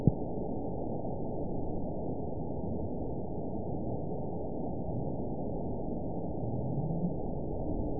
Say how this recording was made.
event 922858 date 04/22/25 time 23:16:15 GMT (1 month, 3 weeks ago) score 9.51 location TSS-AB10 detected by nrw target species NRW annotations +NRW Spectrogram: Frequency (kHz) vs. Time (s) audio not available .wav